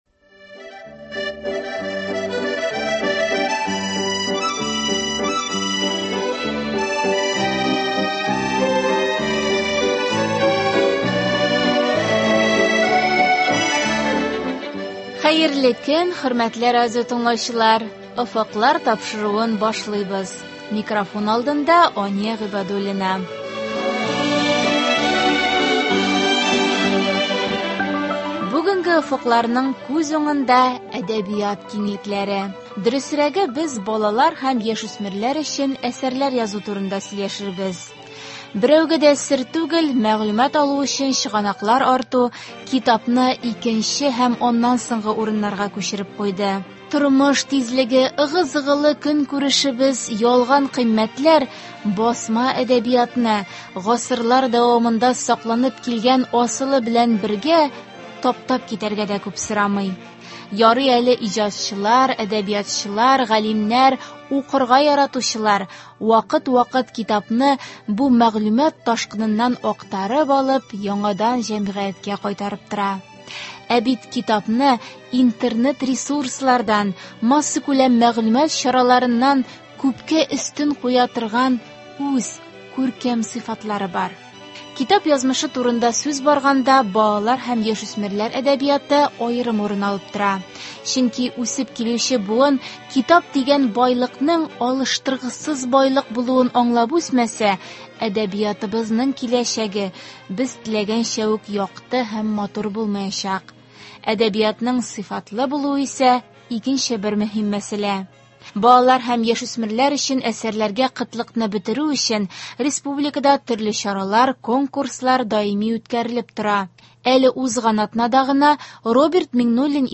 Аның шартлары турында турыдан-туры эфирда
һәм тыңлаучылар сорауларына җавап бирәчәк.